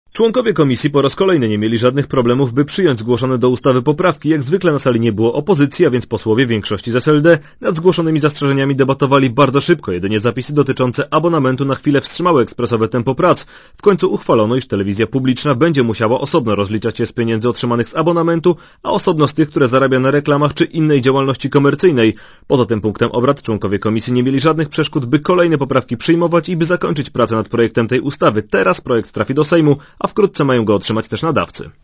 Komentarz audio (125Kb)